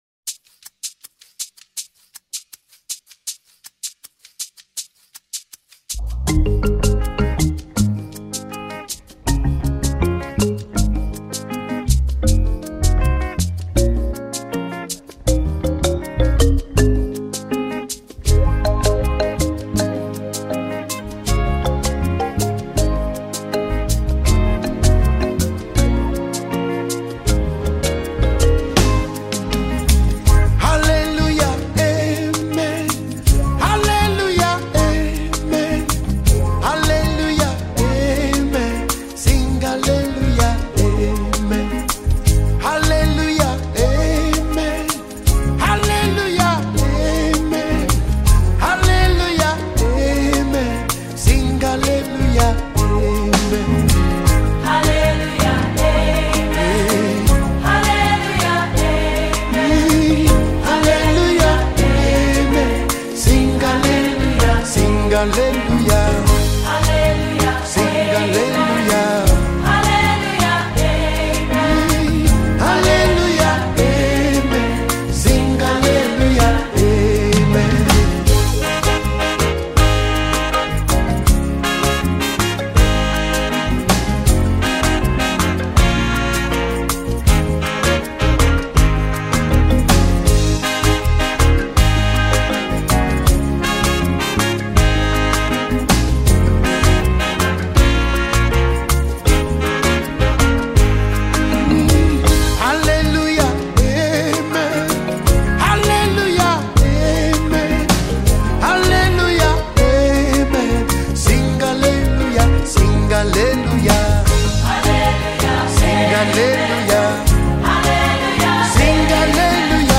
a compilation of praise songs